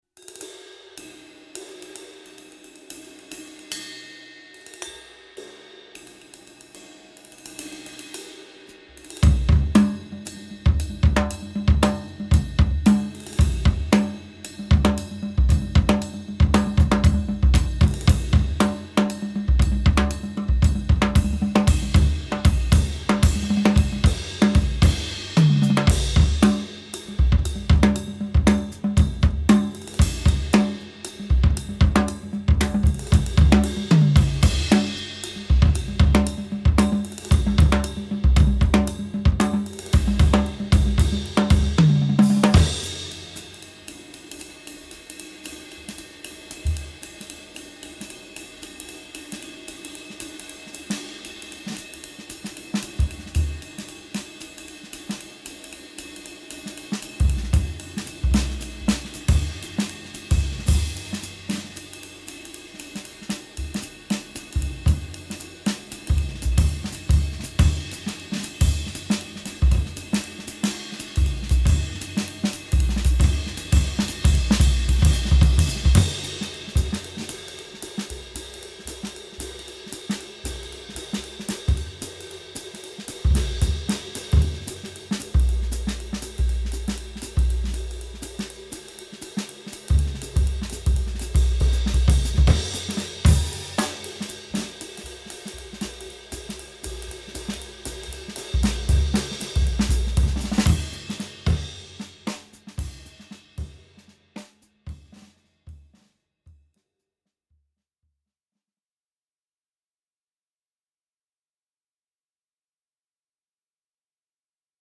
Crescent Cymbals Vintage 20
Click below to hear samples of the various series, and be sure to check out the complete review in the May 2013 issue of Modern Drummer for a more extensive report.